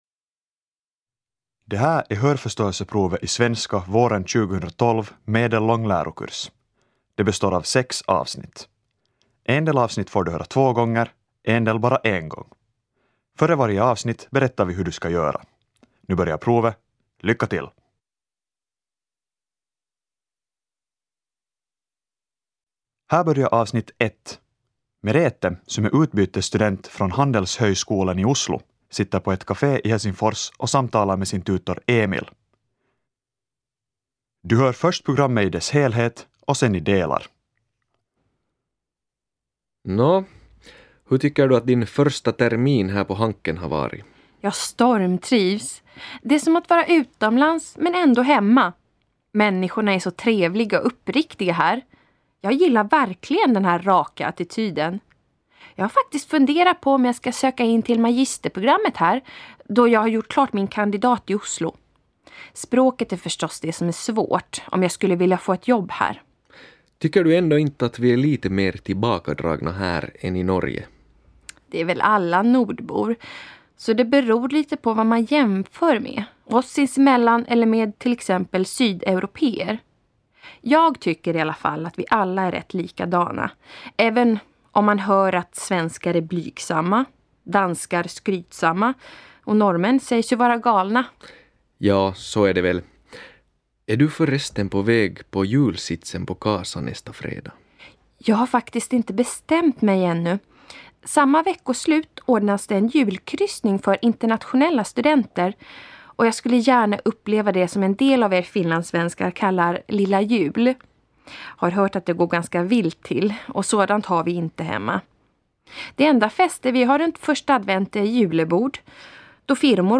Kuuntelu